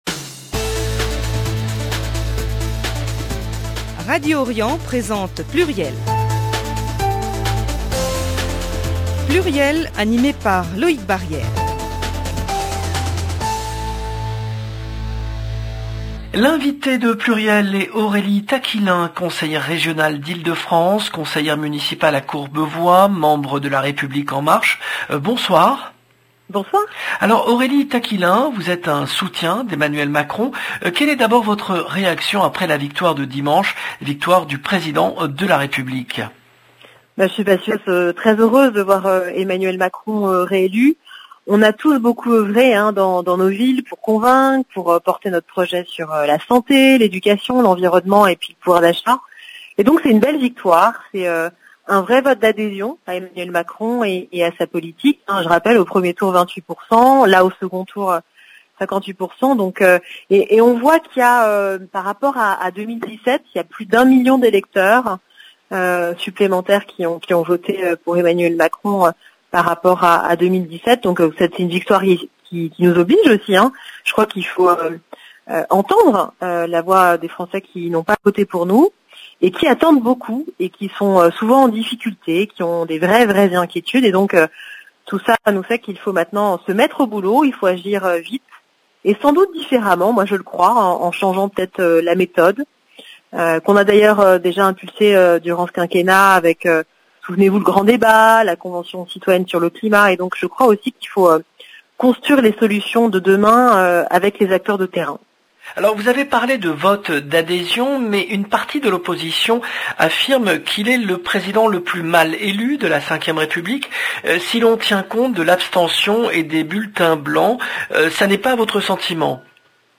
Aurélie Taquillain, conseillère régionale LREM d'Ile-de-France